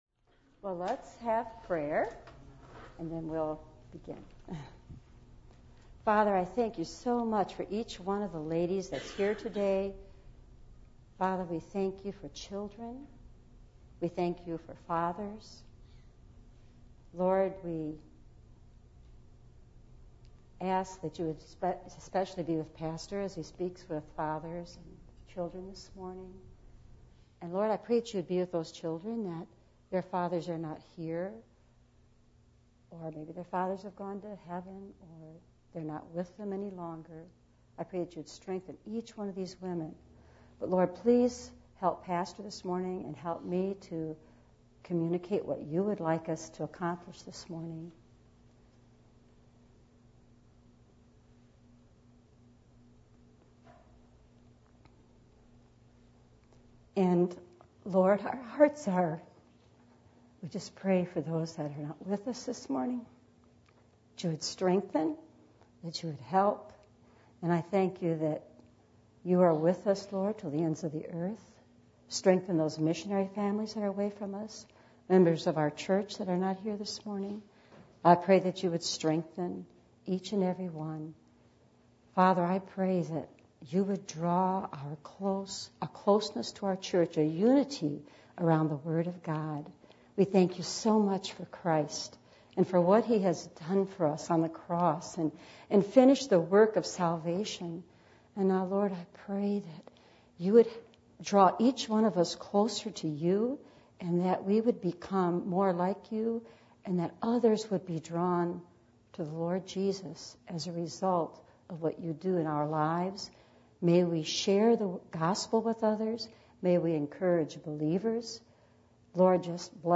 2 Corinthians 7:14 Service Type: Adult Sunday School %todo_render% « What Are We Living For?